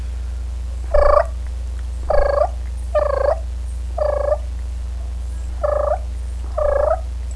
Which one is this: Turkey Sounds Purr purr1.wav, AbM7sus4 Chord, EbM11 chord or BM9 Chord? Turkey Sounds Purr purr1.wav